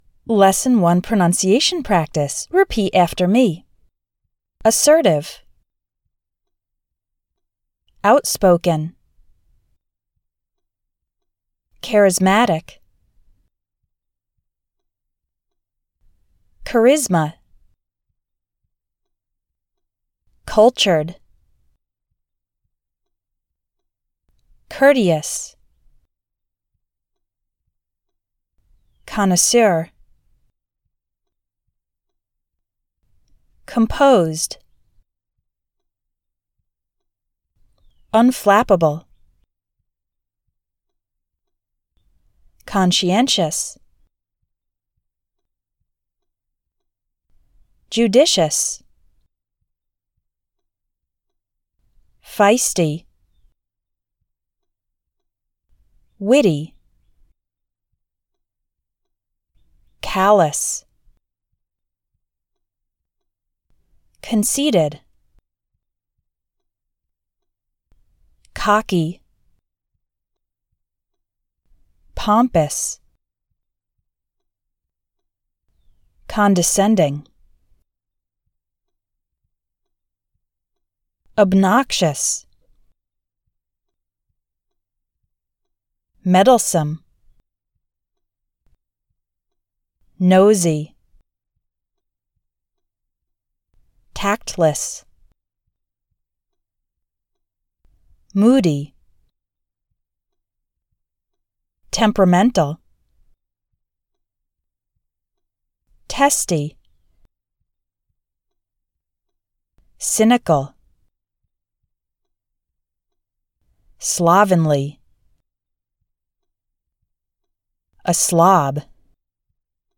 Review & Practice Pronunciation:
Lesson-01-Pronunciation-Practice.mp3